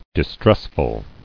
[dis·tress·ful]